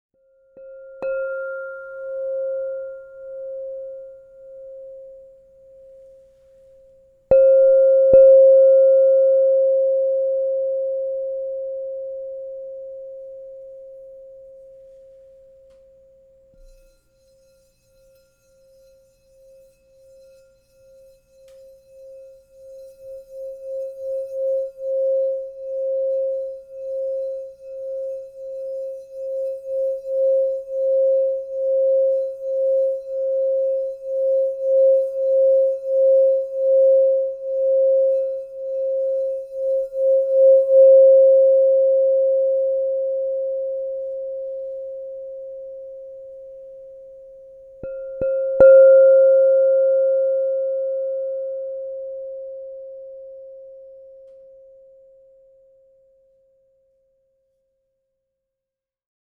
Advanced Selenite, Aqua Aura 6″ C +30 Crystal Tones Singing Bowl
This exceptional instrument combines the clarity of Selenite with the fluidity of Aqua Aura , creating a resonant and transformative sound experience.
Handcrafted with the artistry of Crystal Tones®, this 6-inch singing bowl is compact yet powerful, offering luminous tones ideal for personal meditation, energy work, or professional sound therapy.
Transform your journey with this Advanced Selenite 6″ Crystal Tones® alchemy singing bowl, in the key of C +30.
528Hz (+)